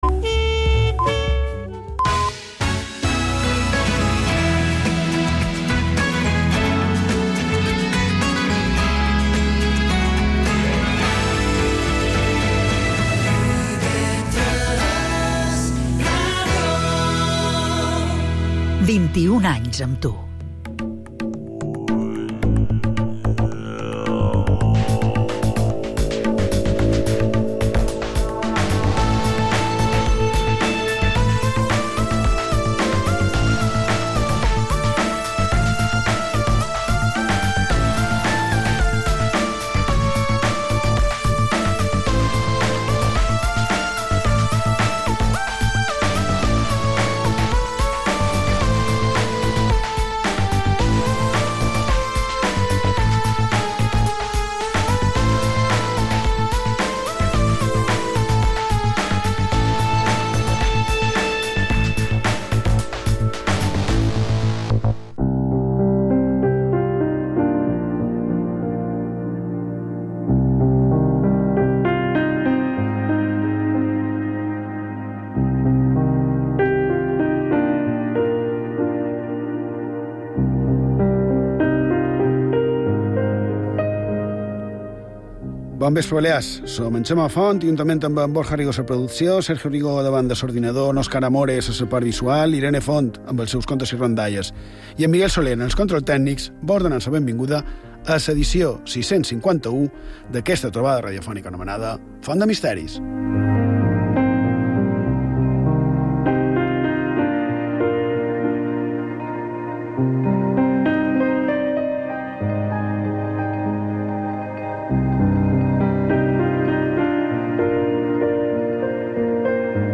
El programa s’enfronta a la quinzena temporada amb un enfocament seriós, rigorós, referències històriques i veus d’experts.